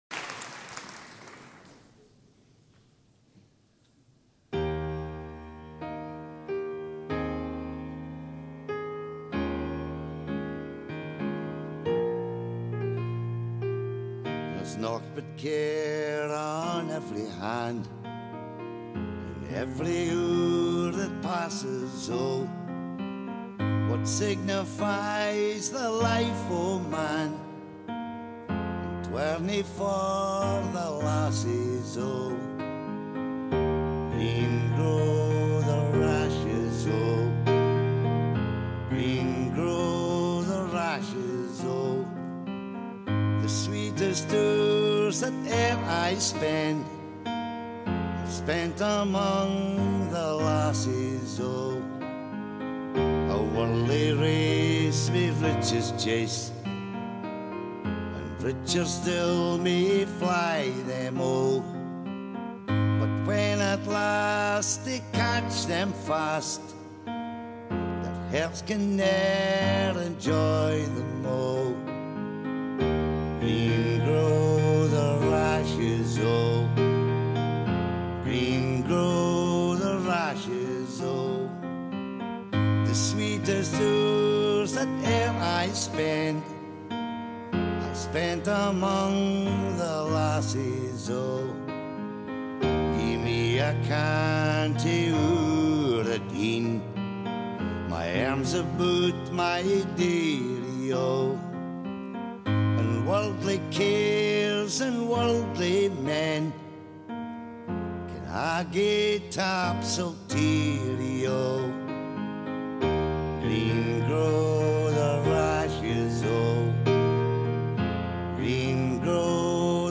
Michael’s version comes from the Celtic Connections Festival in Glasgow a few years back. Pin-drop quiet, he hammers out the tune on a grand piano and sings it superbly.
The thunderous applause at the end tells you all you need to know.
michael_marra_green_grow_the_rashes.mp3